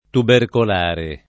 [ tuberkol # re ]